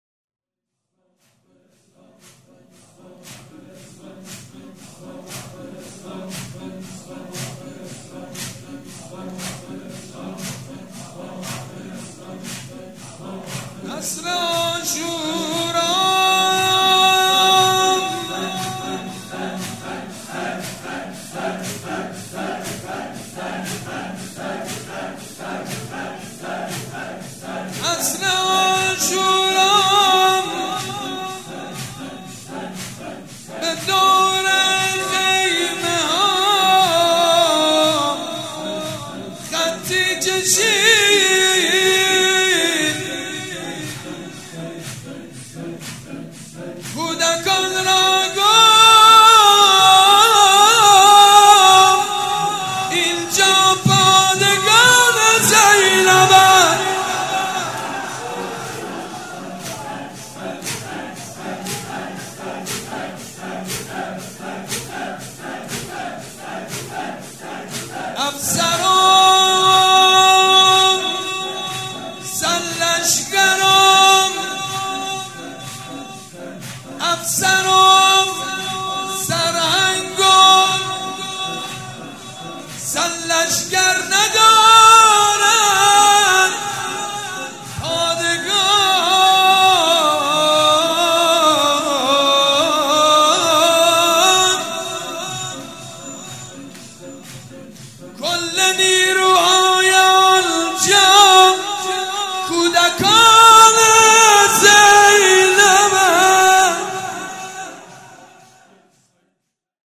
مراسم شهادت حضرت زینب کبری(سلام الله عليها)
مجتمع فرهنگی مذهبی ریحانة الحسین(س)
نغمه خوانی
مداح
حاج سید مجید بنی فاطمه